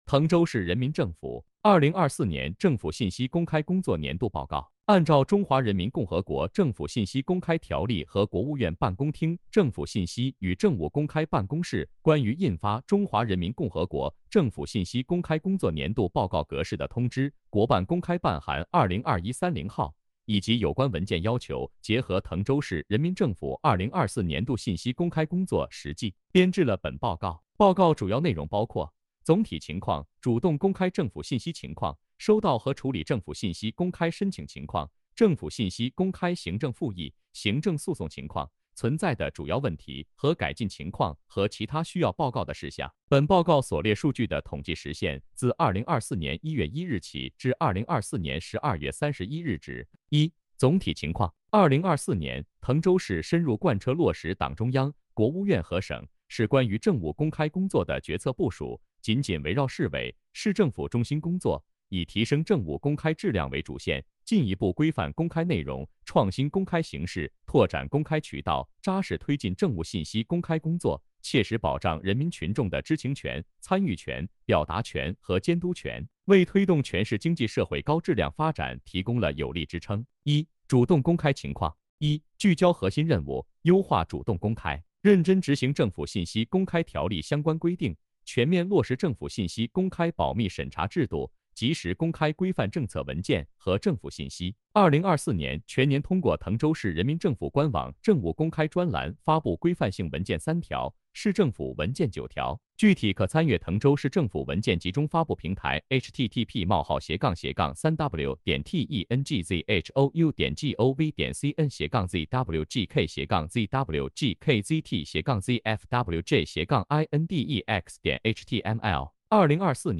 点击接收年报语音朗读 滕州市人民政府2024年政府信息公开工作年度报告 作者： 来自： 时间：2025-02-17 14:18:30 按照《中华人民共和国政府信息公开条例》和《国务院办公厅政府信息与政务公开办公室关于印发<中华人民共和国政府信息公开工作年度报告格式>的通知》（国办公开办函〔2021〕30号）以及有关文件要求，结合滕州市人民政府2024年度信息公开工作实际，编制了本报告。